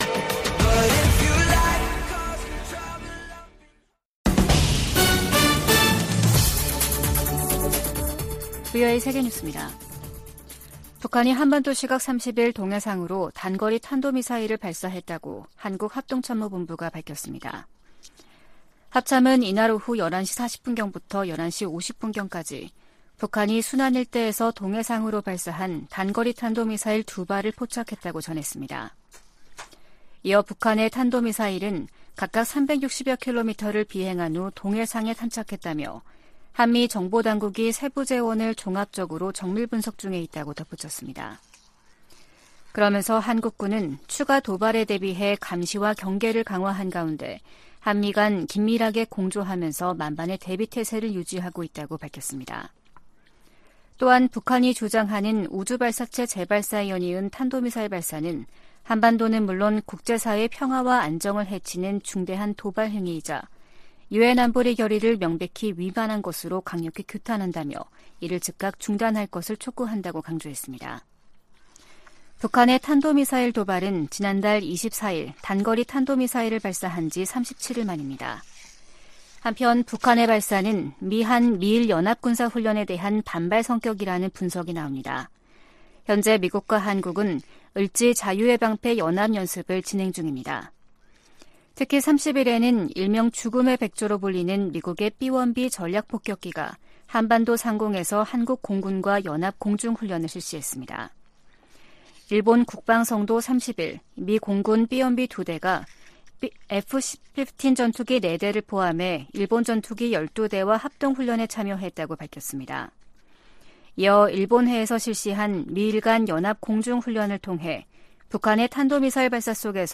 VOA 한국어 아침 뉴스 프로그램 '워싱턴 뉴스 광장' 2023년 8월 31일 방송입니다. 미국과 한국, 일본은 한반도 사안을 넘어 국제적으로 안보협력을 확대하고 있다고 백악관 조정관이 말했습니다. 미 국방부는 위성 발사 같은 북한의 모든 미사일 활동에 대한 경계를 늦추지 않을 것이라고 강조했습니다. 국제 핵실험 반대의 날을 맞아 여러 국제 기구들이 북한의 핵과 미사일 개발을 규탄했습니다.